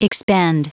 Prononciation du mot expend en anglais (fichier audio)
Prononciation du mot : expend